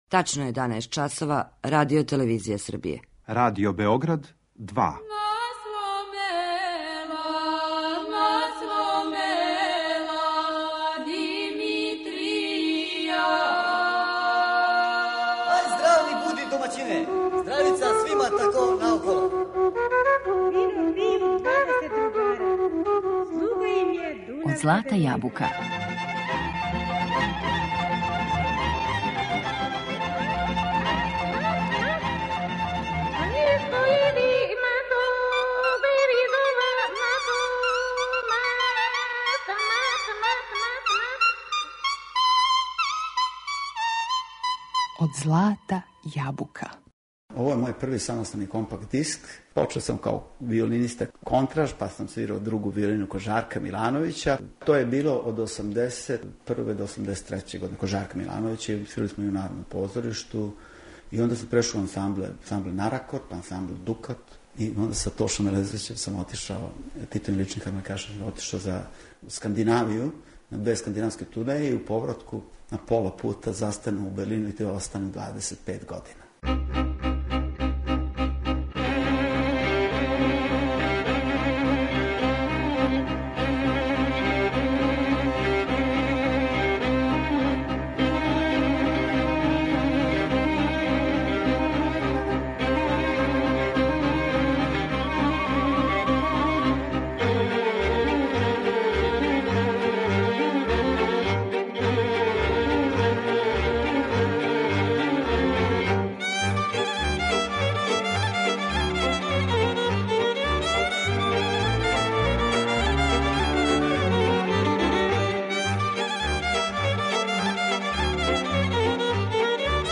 виолинисте
дванаест кола и народних игара